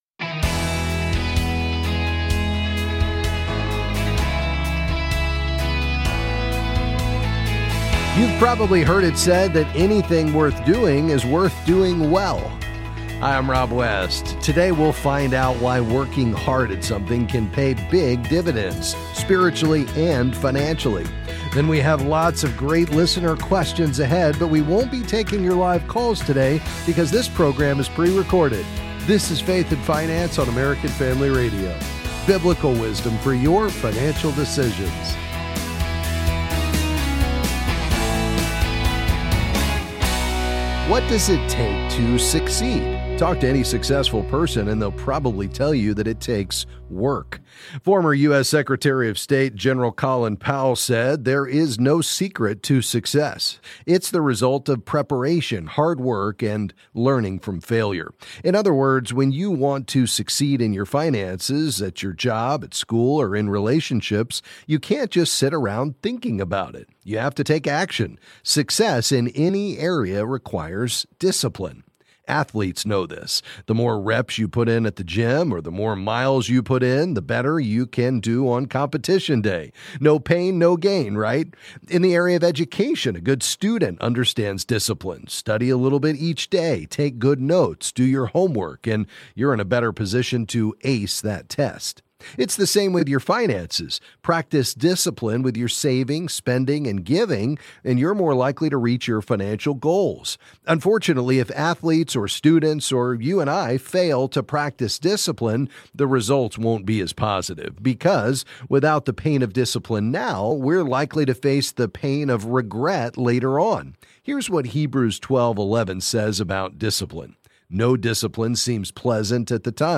Then he answers questions on various financial topics.